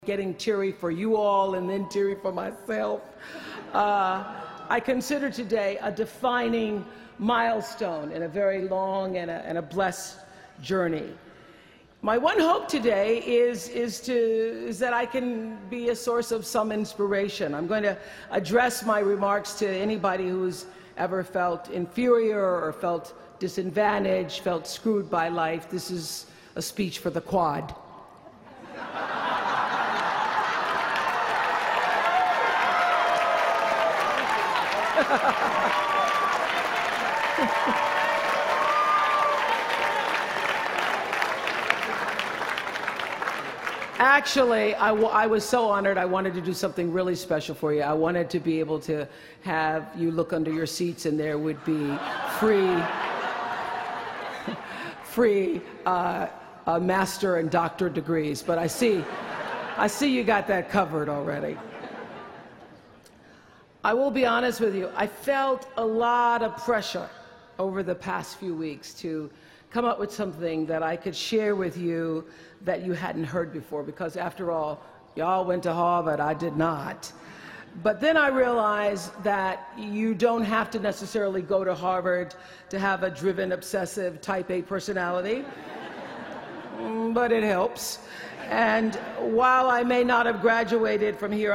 公众人物毕业演讲第353期:奥普拉2013在哈佛大学(2) 听力文件下载—在线英语听力室